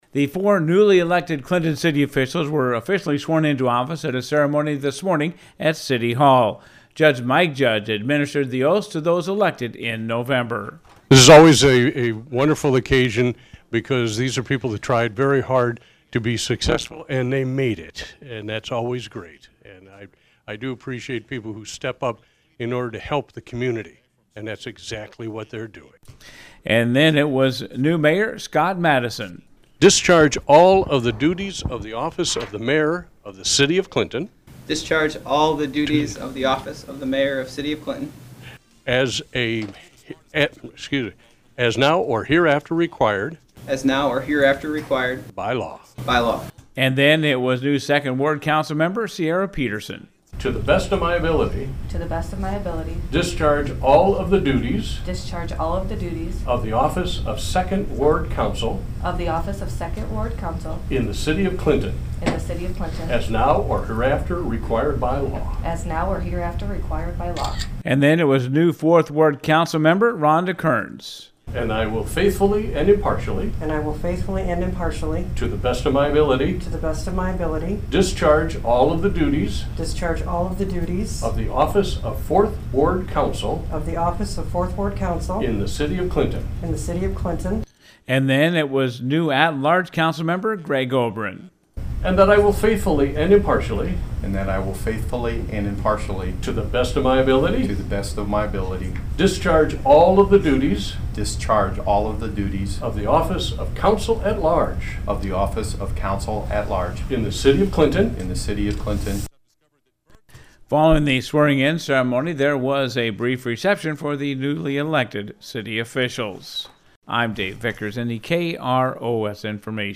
Swear-In.mp3